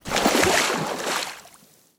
255081e1ee Divergent / mods / Soundscape Overhaul / gamedata / sounds / material / human / step / t_water3.ogg 61 KiB (Stored with Git LFS) Raw History